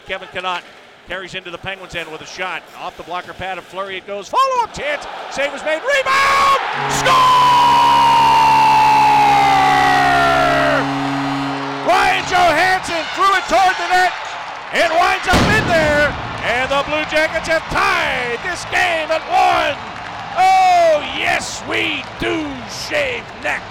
Ryan Johansen scores to tie the game 1-1 against the Pittsburgh Penguins inside Nationwide Arena!